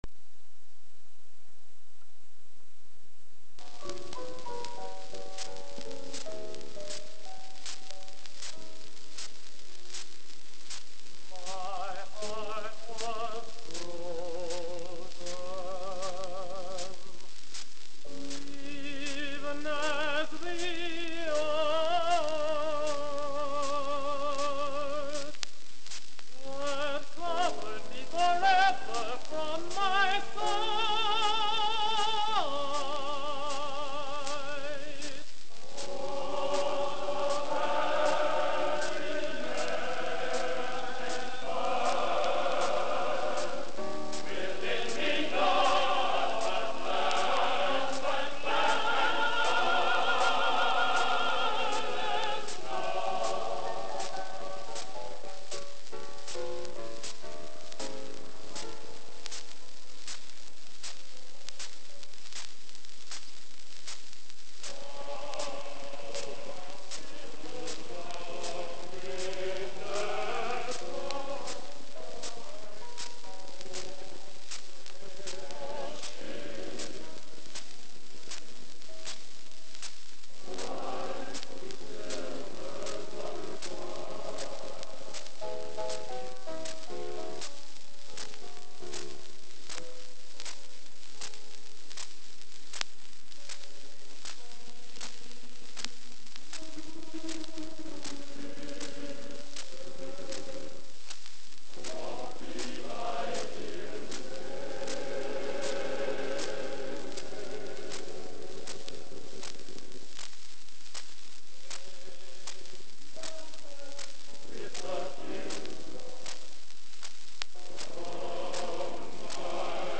Genre: | Type: Studio Recording